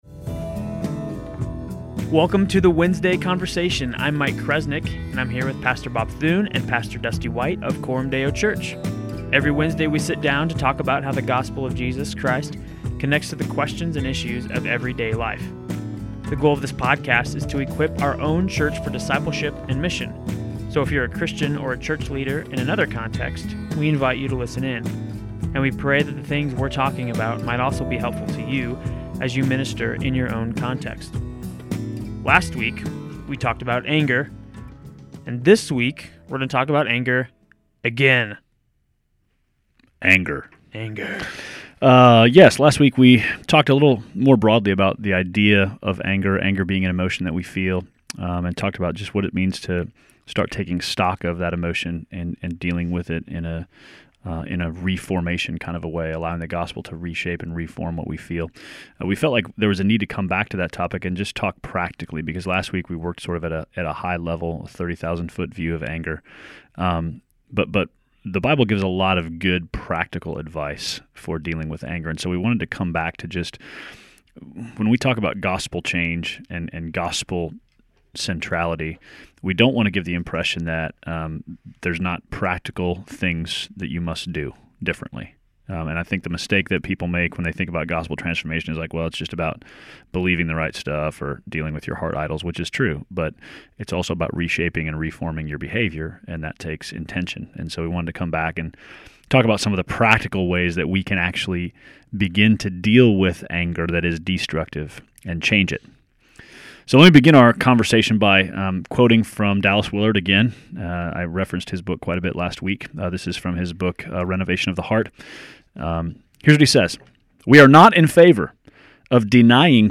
During this week's conversation the guys sat down and talked more in-depth about and how the Bible tells us to deal with anger.